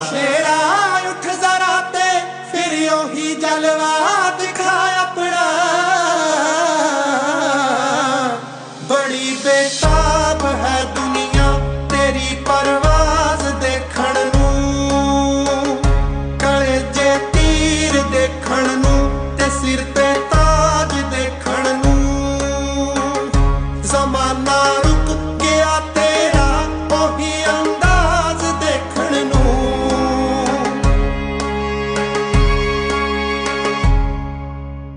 Punjabi Ringtones
Soulfull Ringtones